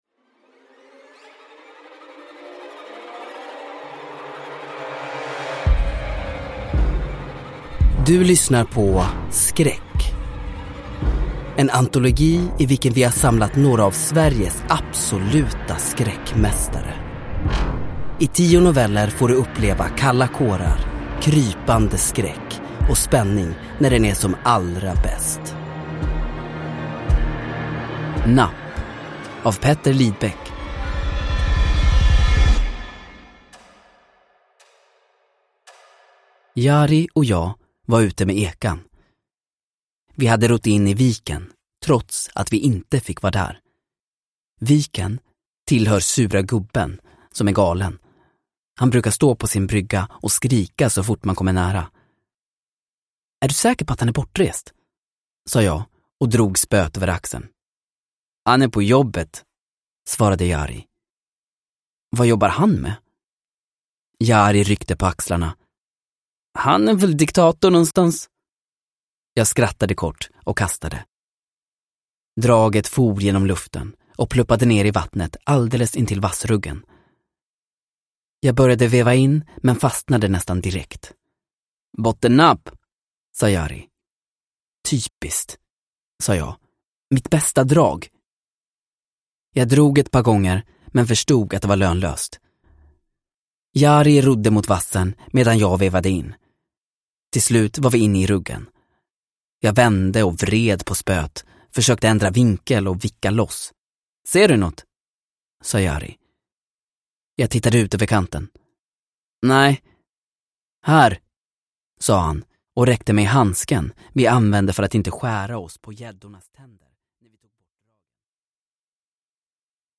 Skräck - Napp – Ljudbok – Laddas ner